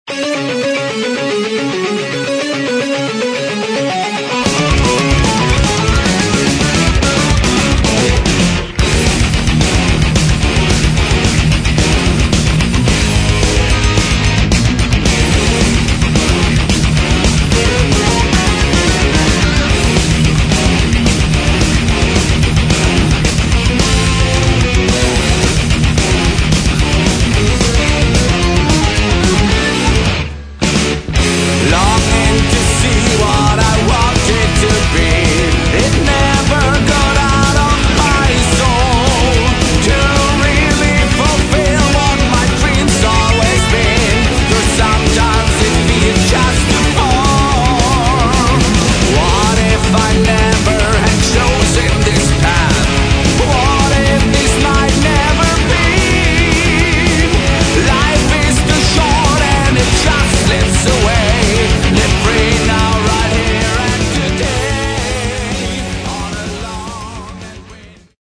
Metal
вокал
барабаны
клавиши
гитара
бас